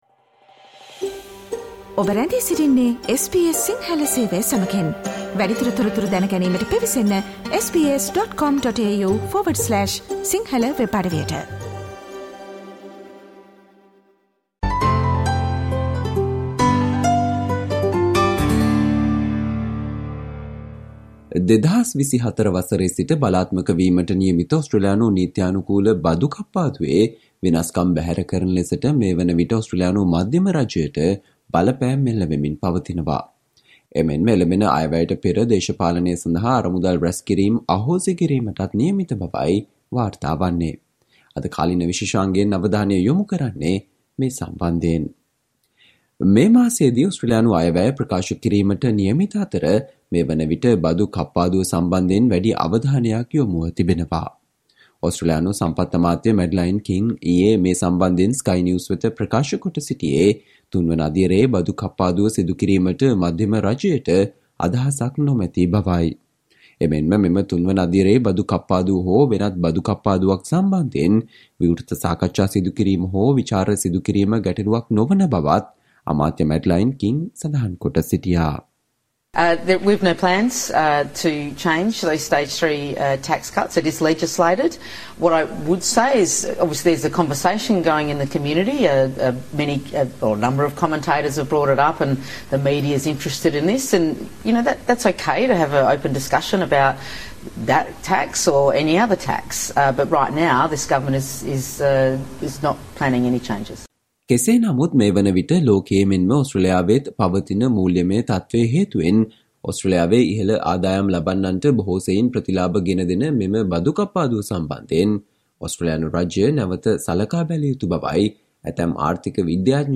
Today - 10 October, SBS Sinhala Radio current Affair Feature on Tax cuts chat takes centre stage and a move to ban fundraising from parliament